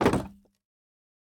Minecraft Version Minecraft Version latest Latest Release | Latest Snapshot latest / assets / minecraft / sounds / block / bamboo_wood_door / toggle1.ogg Compare With Compare With Latest Release | Latest Snapshot